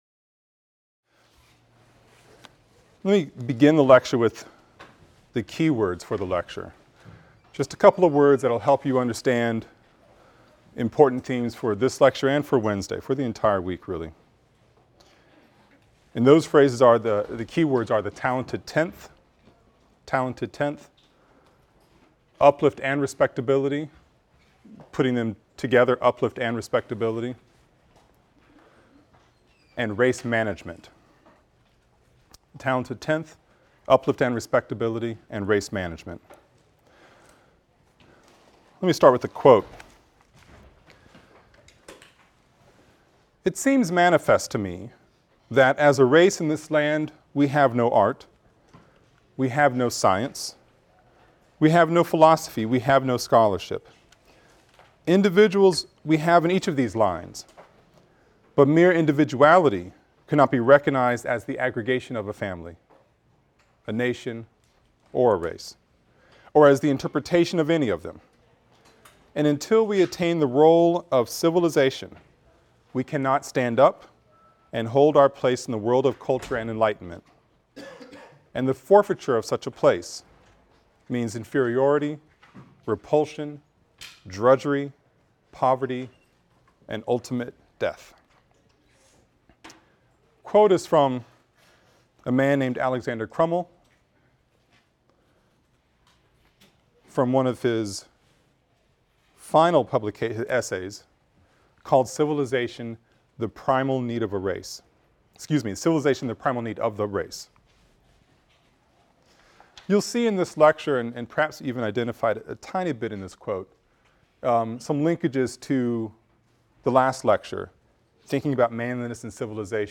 AFAM 162 - Lecture 5 - Uplift, Accommodation, and Assimilation | Open Yale Courses